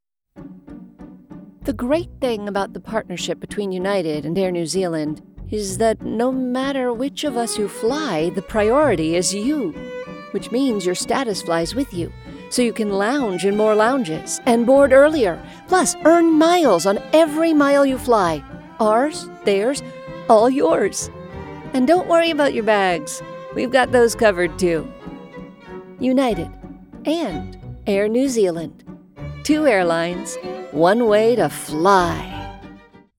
UAL demo commercial